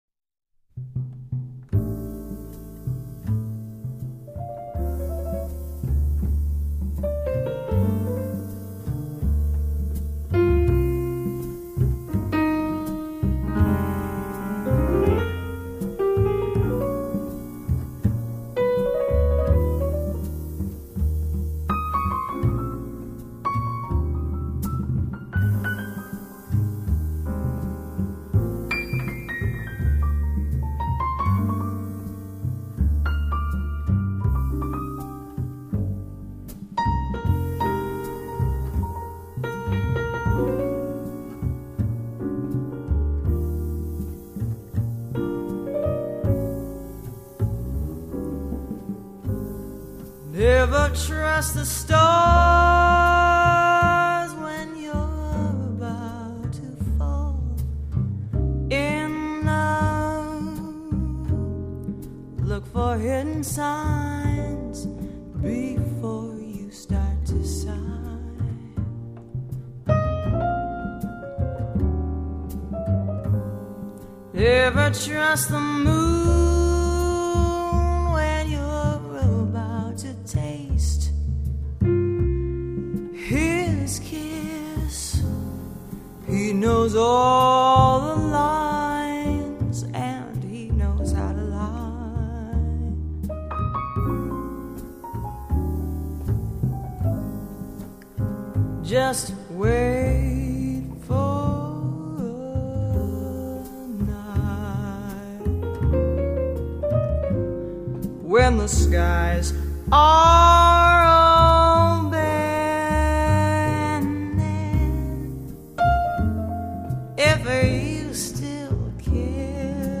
音乐类型：爵士乐